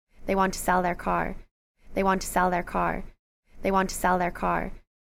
SELL with considerable lowering (non-local speaker)
SELL_with_considerable_lowering_(non-local_speaker).mp3